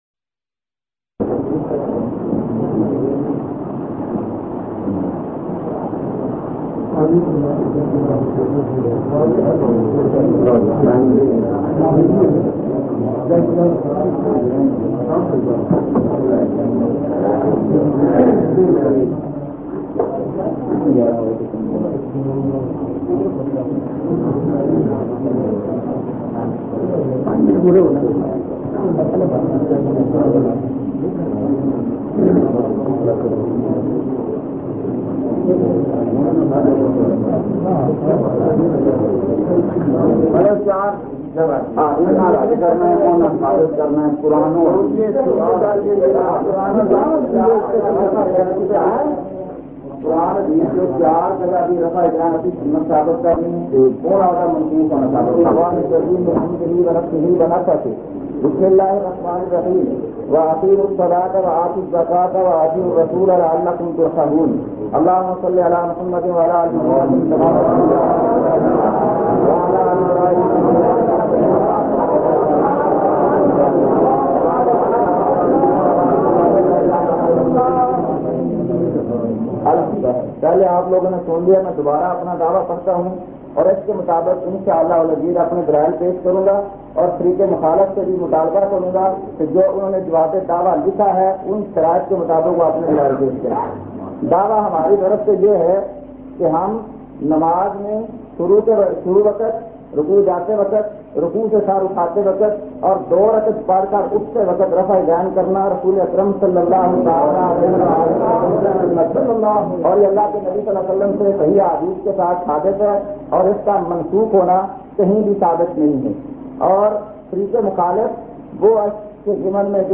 Audio Munazara